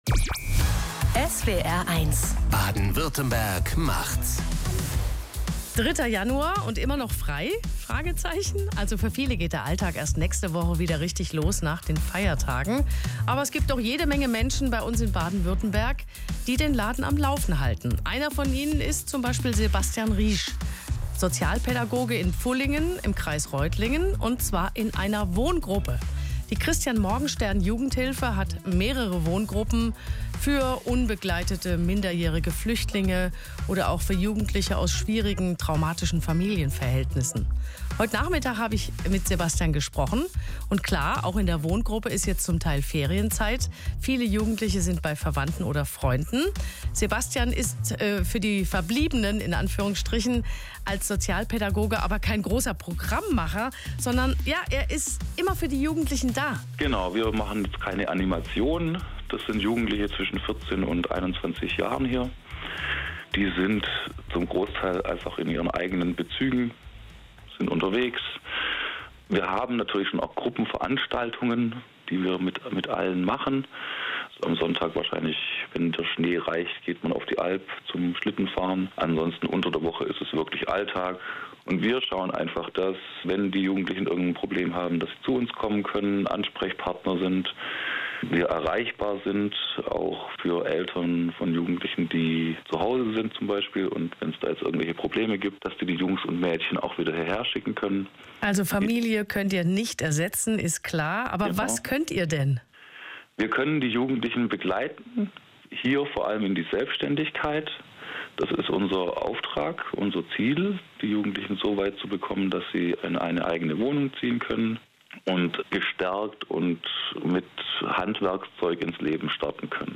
Wir sind im Radio!